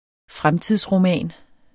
Udtale [ ˈfʁamtiðs- ]